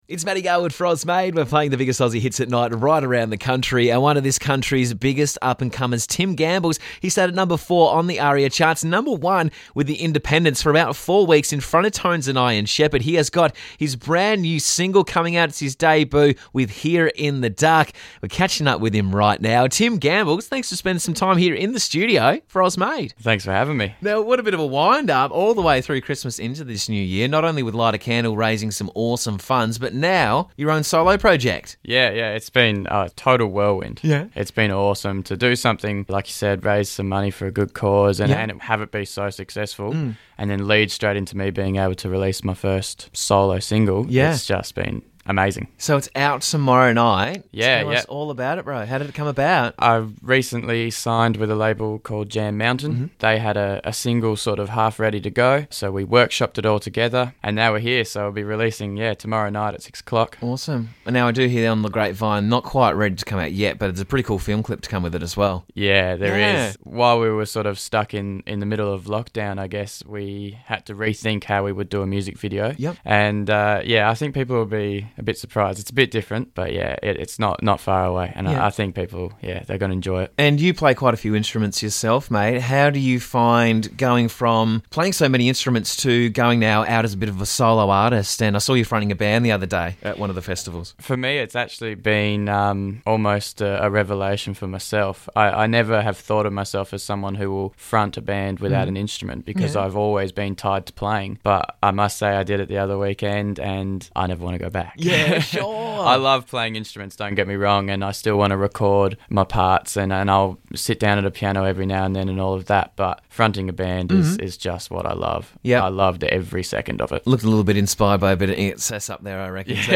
multi-instrumentalist
with shades of Harry Styles, Bowie and Queen.